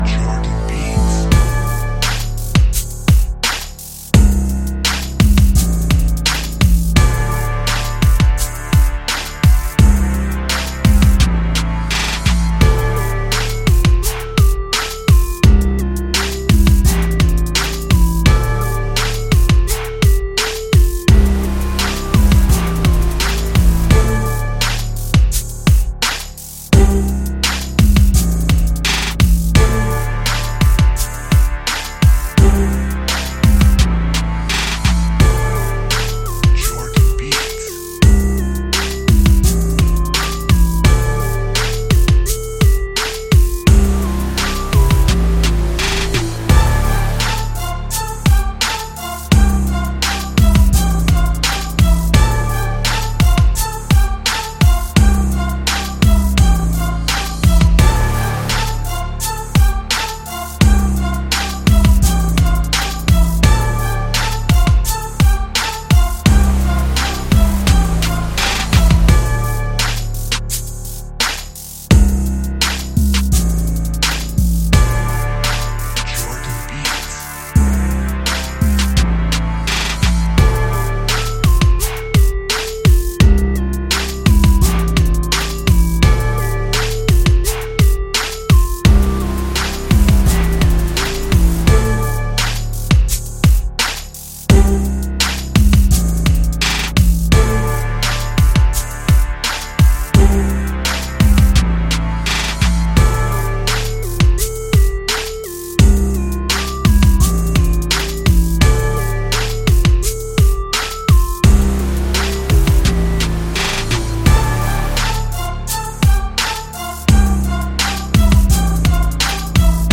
Powerful_Choir_Rap_Beat.mp3